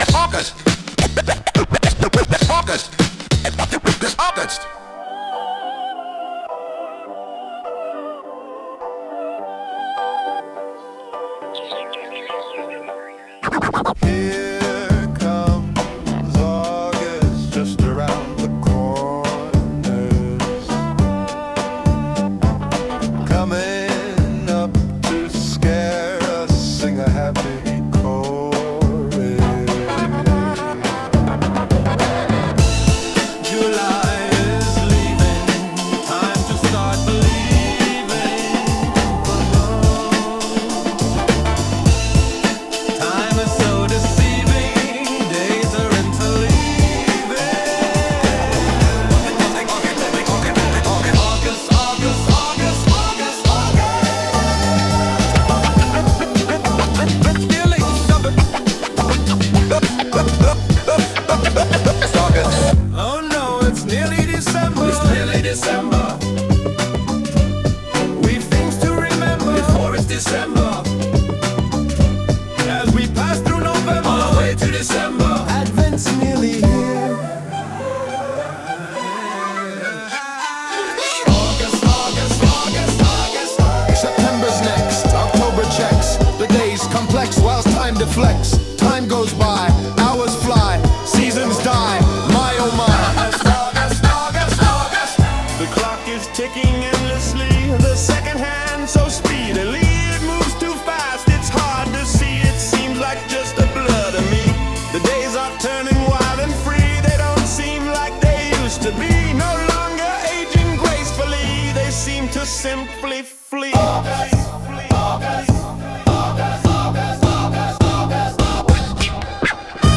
Sung by Suno
August_Incoming_(Remix)_mp3.mp3